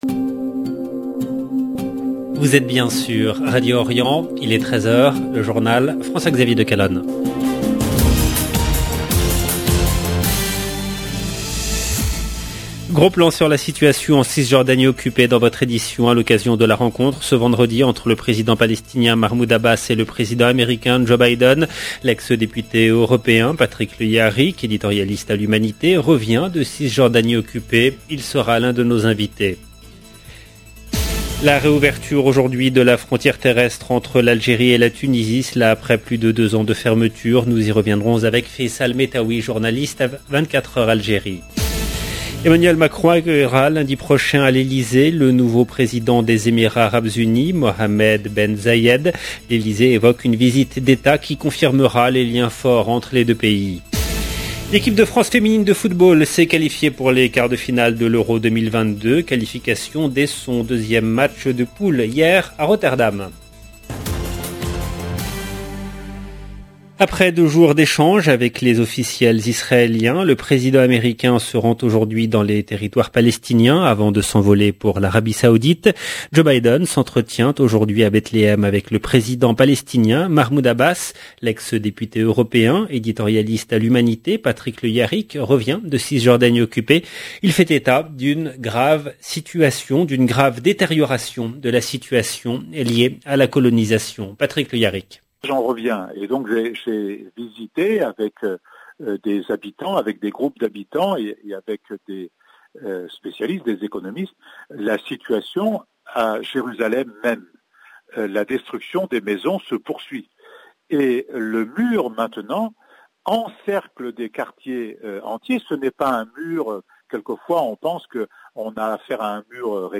LB JOURNAL EN LANGUE FRANÇAISE journal présenté par